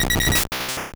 Cri de Doduo dans Pokémon Or et Argent.